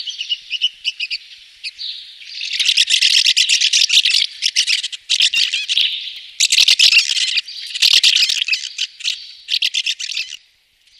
Jaskółka oknówka - Delichon urbicum